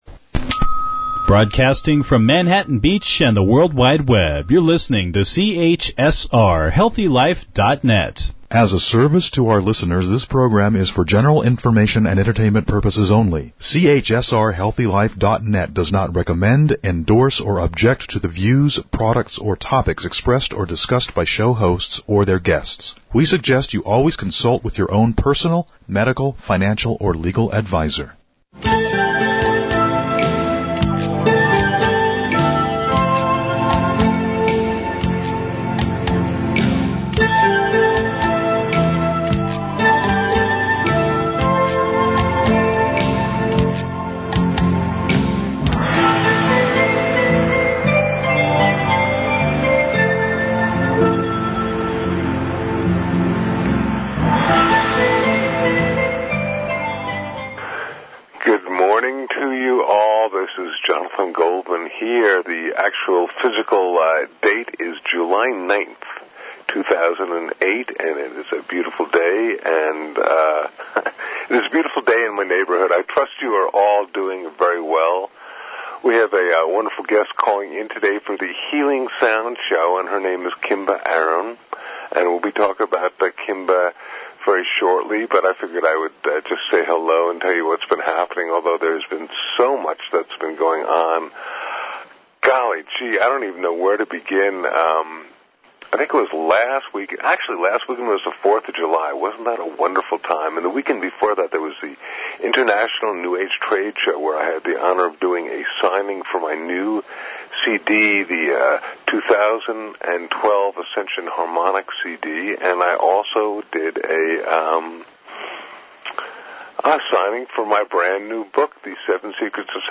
Audio Interviews